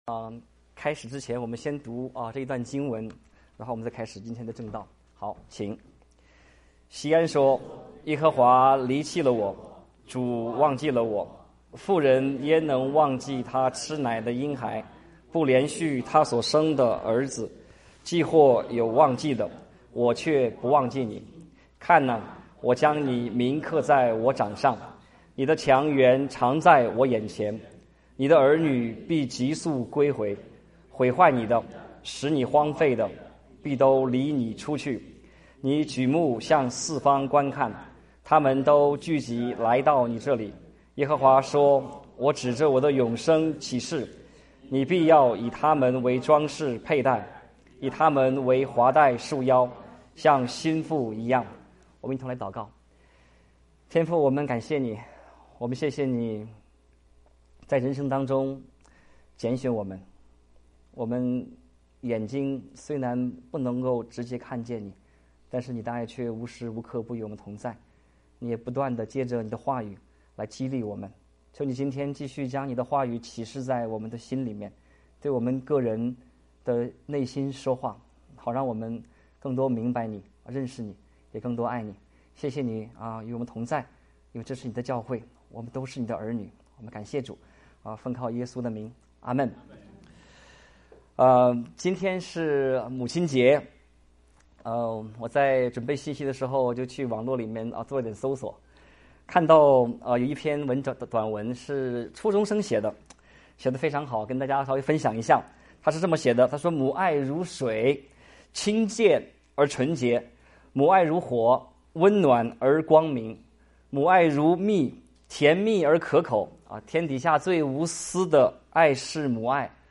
講員